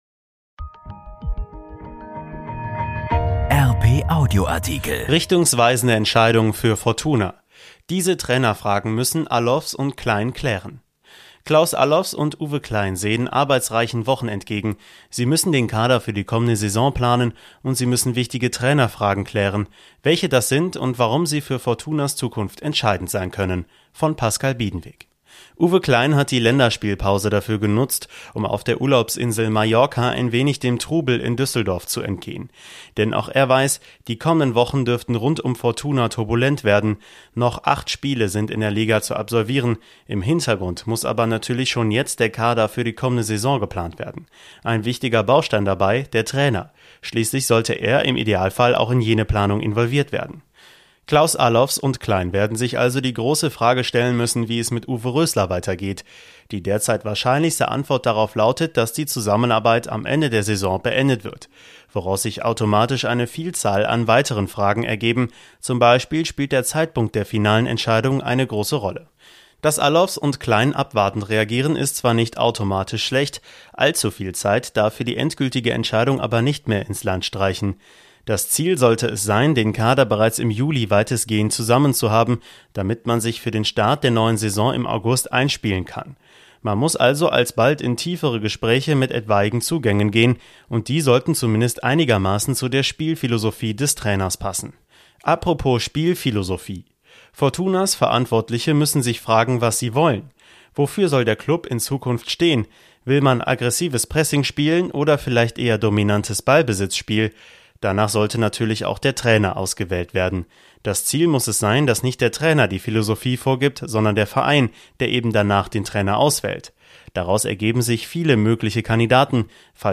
Hier findet Ihr unsere besten Artikel zum Hören: Unser Journalismus vorgelesen.